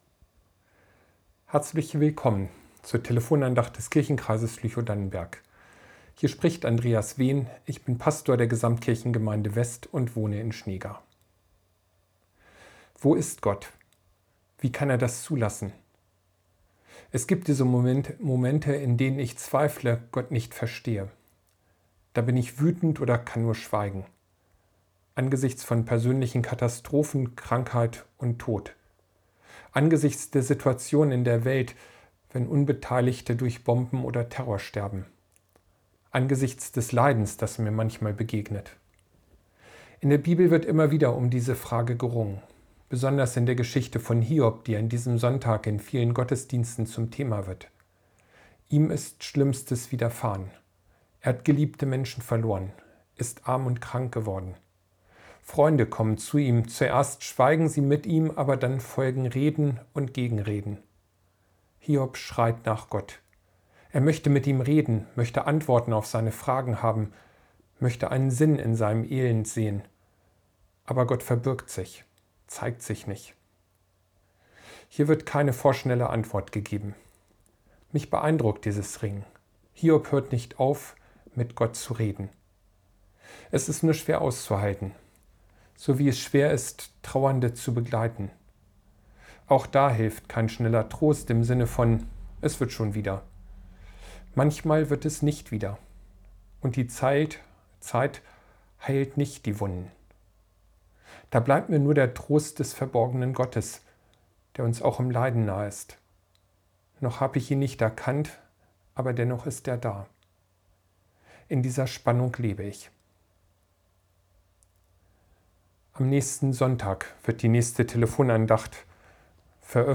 Telefon-Andacht